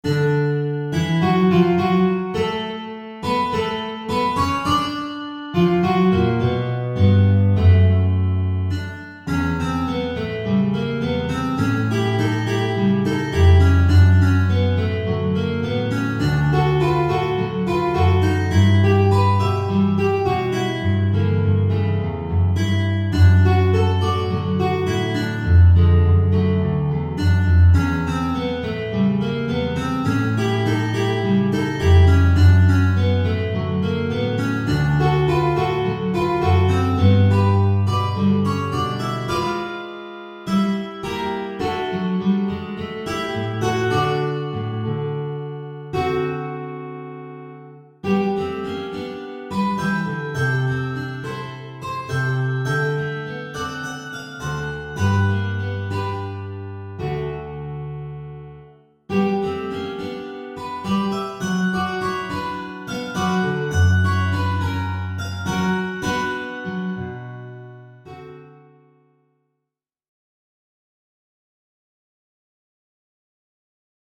Guitar Quartet
This ragtime classic